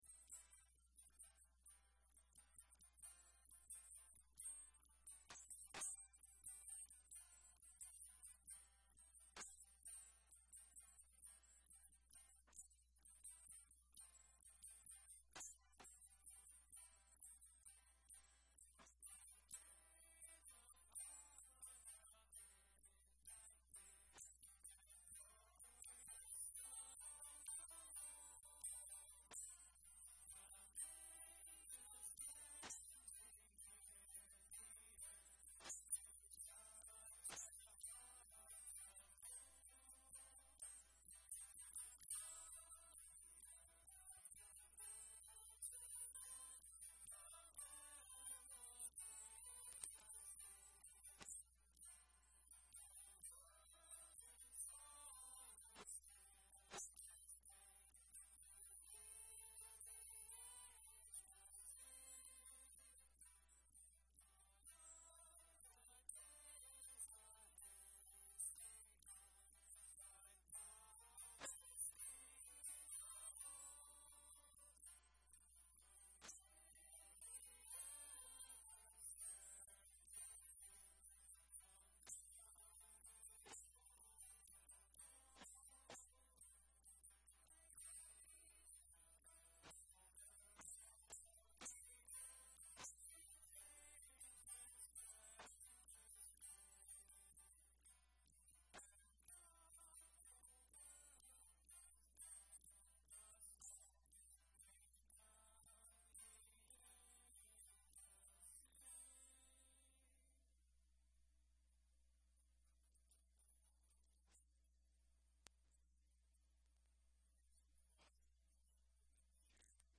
Grace Fellowship Church celebrates the birth of Jesus Christ with our Christmas Eve Candlelight Service.